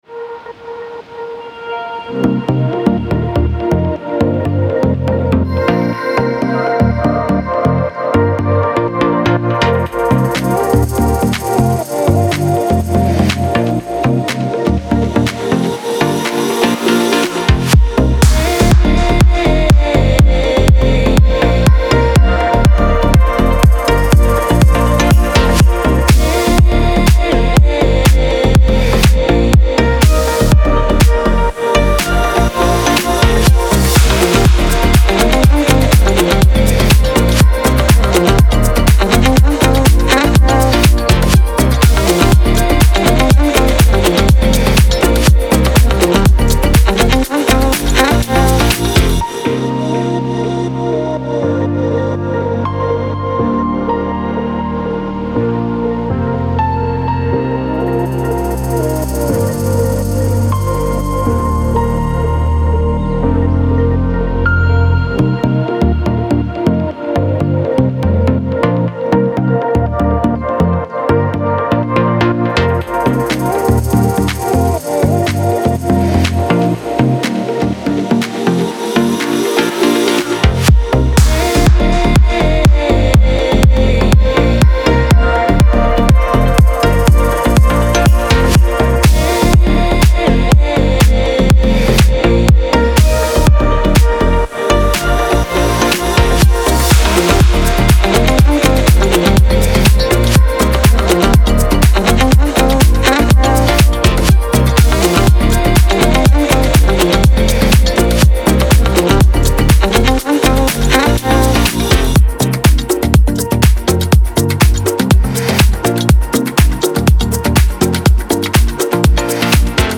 موسیقی بی کلام الکترونیک پاپ موسیقی بی کلام انگیزشی